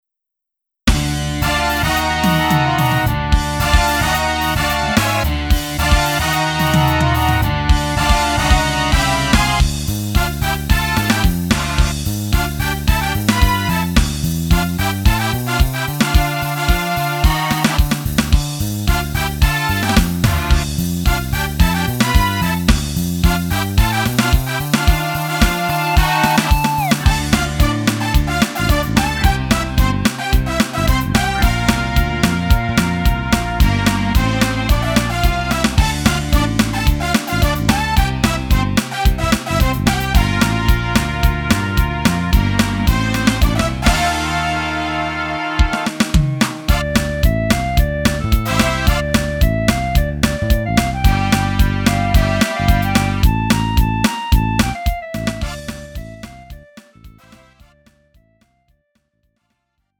음정 -1키 3:49
장르 가요 구분 Lite MR